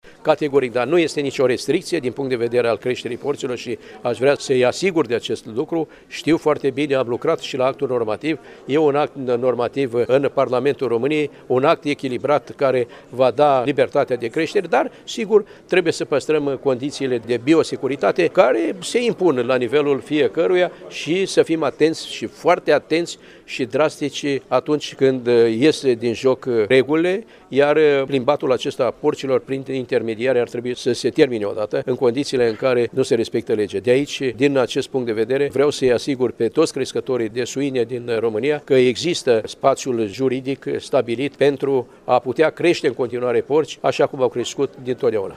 Iaşi – Ministrul Agriculturii, Petre Daea, s-a întîlnit cu fermieri şi agricultori din judeţ